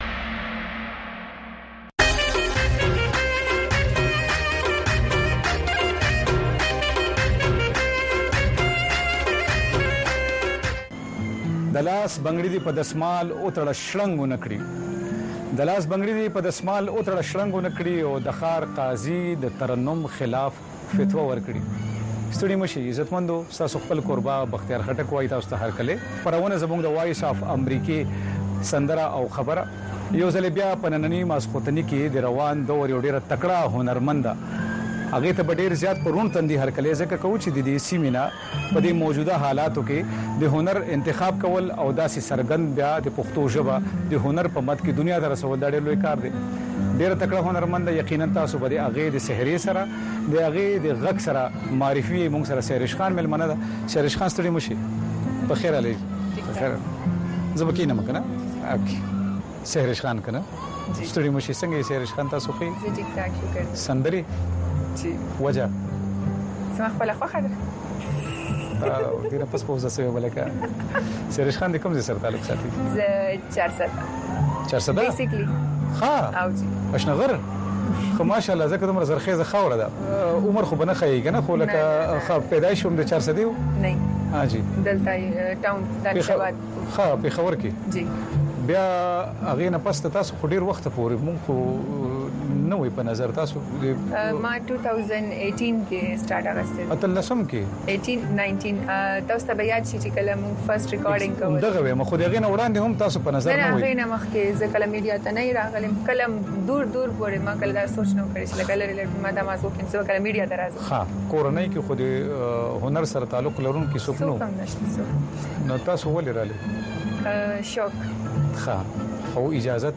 دې یو ساعته پروگرام کې تاسو خبرونه او د هغې وروسته، په یو شمېر نړیوالو او سیمه ایزو موضوگانو د میلمنو نه پوښتنې کولی شۍ.